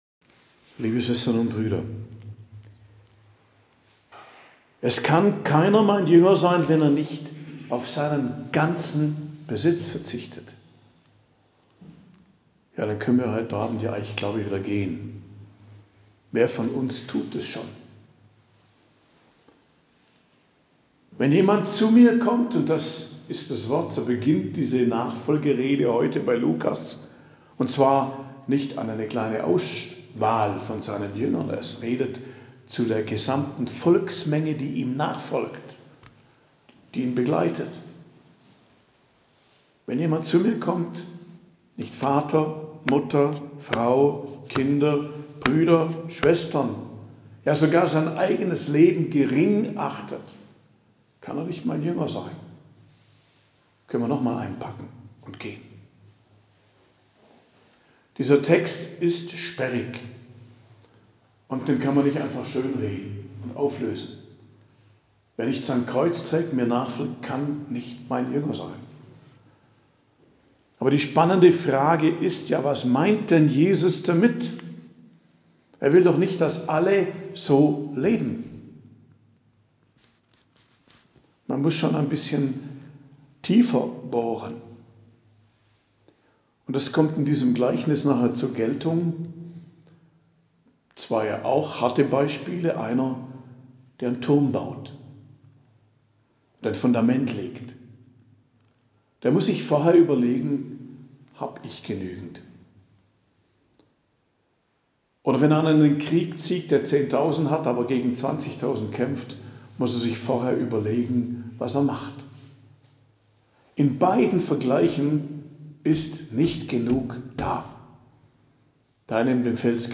Predigt am Mittwoch der 31. Woche i. J., 8.11.2023